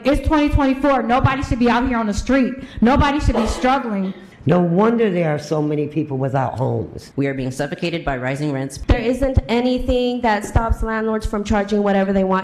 Residents told commissioners that it’s busting their budgets.